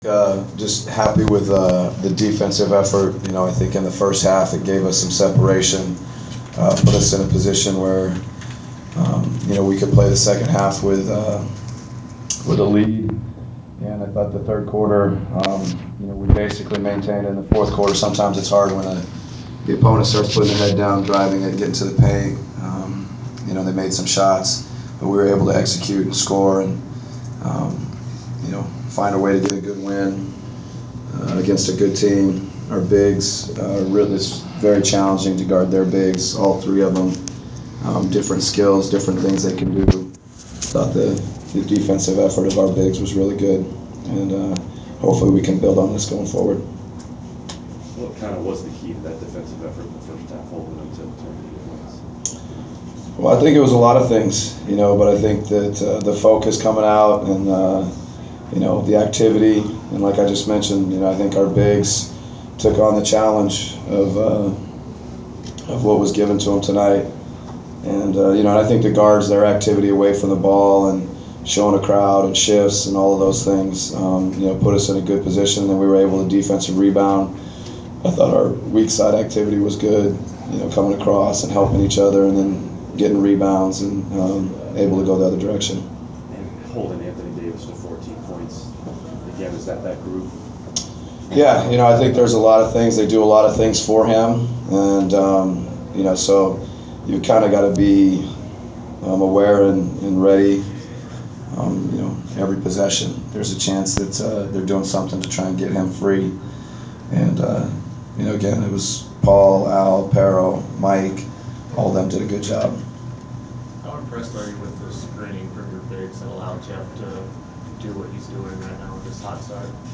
Inside the Inquirer: Postgame interview with Atlanta Hawks’ coach Mike Budenholzer (11/28/14)